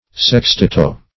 Sextetto \Sex*tet"to\